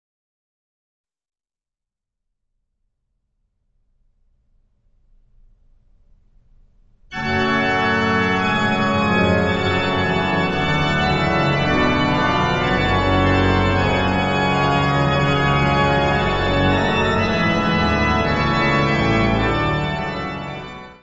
Organ works
Notas:  Este disco foi gravado ao vivo na Basilica de St. Alexander und Theodor, Benediktinerabtei em Ottobeuren na Alemanha, durante o mês de Maio de 1998; O orgão utilizado na gravação foi construído por Johann Andreas Silbermann, entre 1756-1761; No do Serviço de Aquisições e Tratamento Técnico